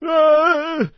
PeasantWarcry1.mp3